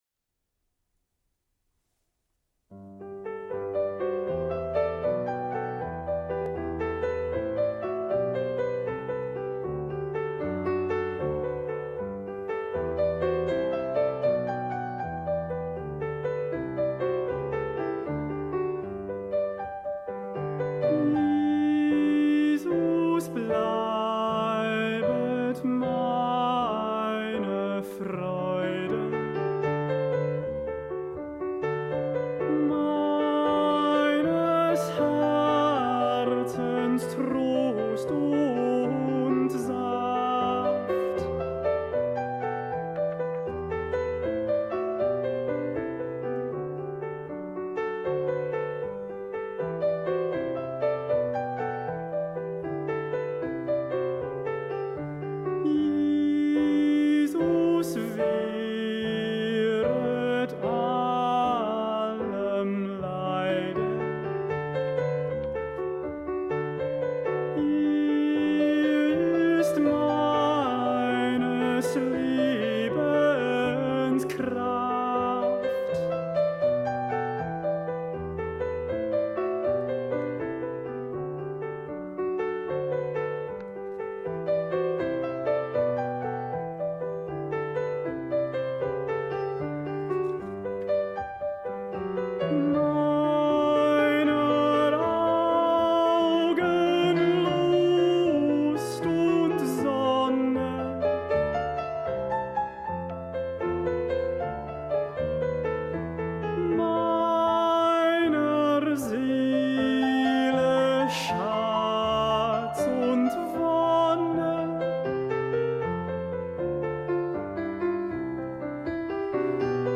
tenor
cantate-147-jesus-bleibet-tenor.mp3